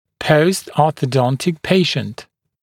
[ˌpəustˌɔːθə’dɔntɪk ‘peɪʃnt][ˌпоустˌо:сэ’донтик ‘пэйшнт]пациент, прошедший ортодонтическое лечение